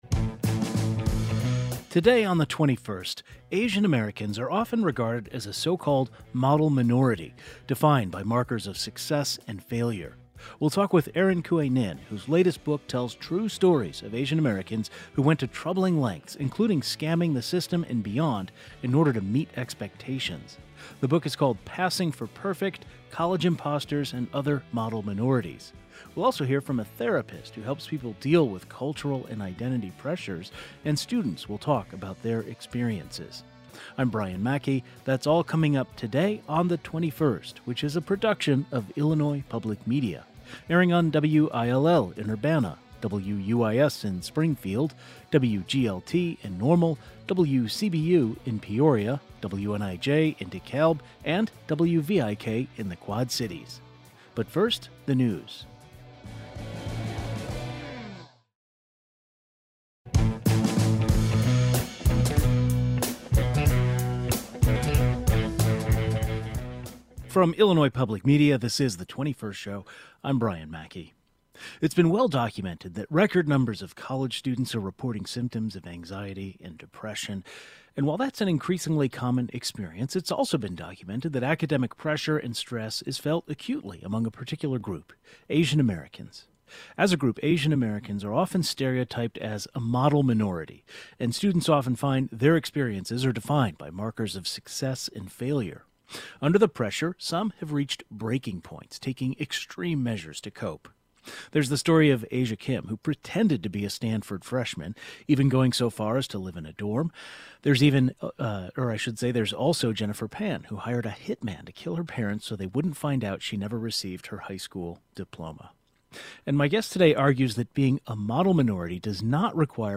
The 21st was joined by the author of a book exploring the myth of the model minority and the students who take extreme measures to cope, as well as a clinical social worker and a student who shared her own experiences with the pressure often placed on Asian American students.